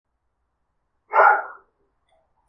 077766_dog Barking Distance Sound Effect Download: Instant Soundboard Button
Dog Barking Sound553 views